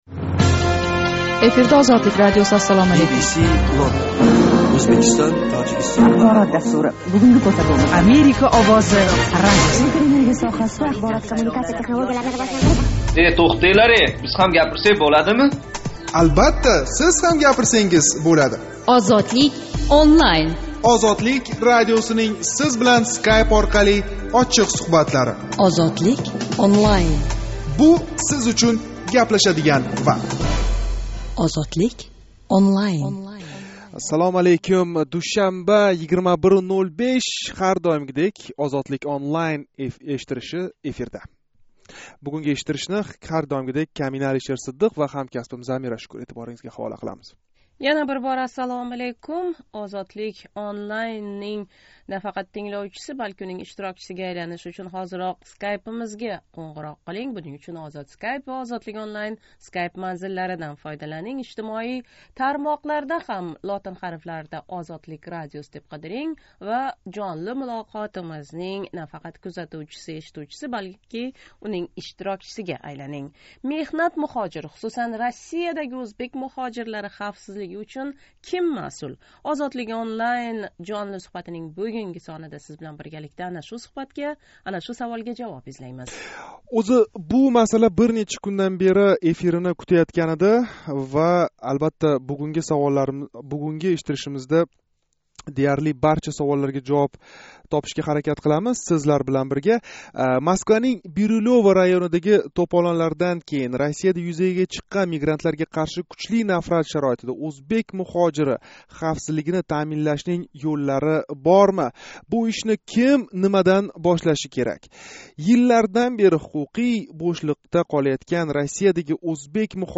Россия иқтисоди “бели”ни ушлаб турган бу муҳожирлар ҳуқуқий ва жисмоний хавфсизлиги ким томонидан¸ қандай таъминланиши керак? Душанба¸ 21 октябрь куни Тошкент вақти билан 21:05 да Skypeдаги OzodSkype ва OzodlikOnline манзиллари орқали шу мавзуда гаплашдик.